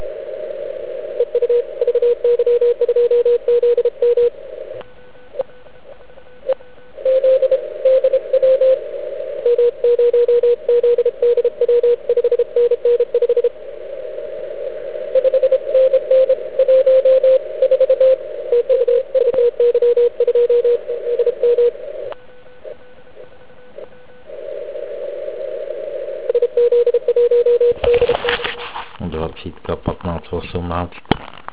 A jak je slyšet na 160m i na "pádlové" antény v OK? Našel jsem ve své sbírce několik nahrávek jak jsem ho slyšel já.
Úžasný signál.